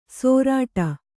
♪ sōrāṭa